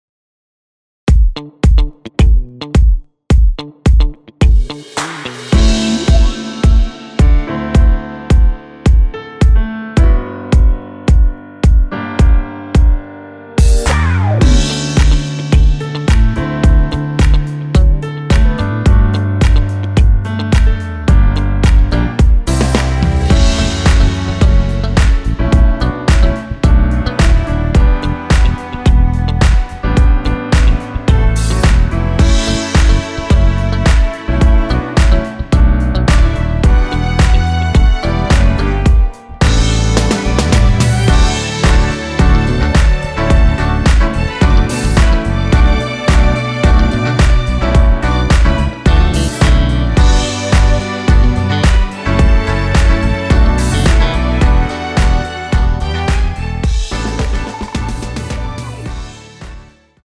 원키에서(-2)내린 MR입니다.
앞부분30초, 뒷부분30초씩 편집해서 올려 드리고 있습니다.
중간에 음이 끈어지고 다시 나오는 이유는
축가 MR